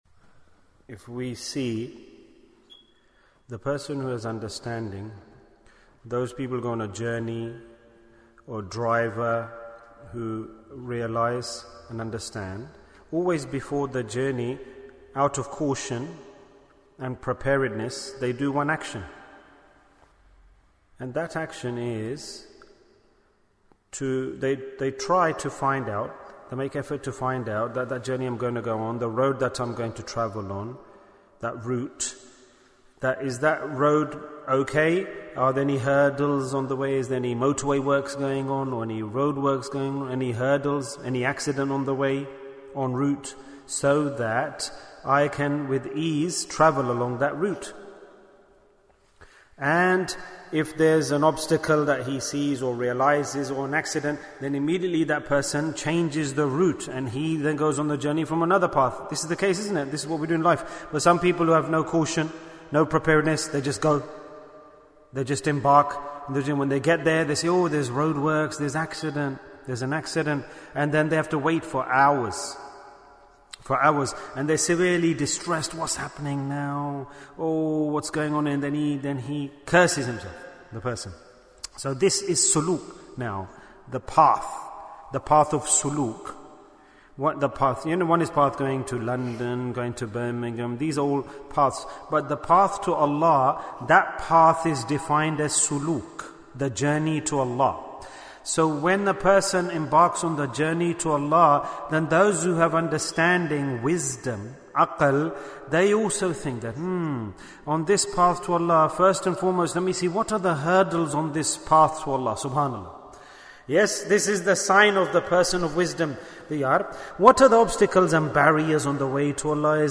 Bayan, 38 minutes 10th June, 2021 Click for Urdu Download Audio Comments How Can We Remove Spiritual Barriers?